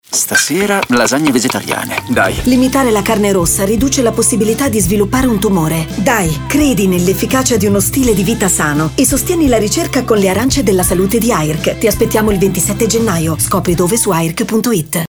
Audiolibro
Interpretato, morbido, naturale/semplice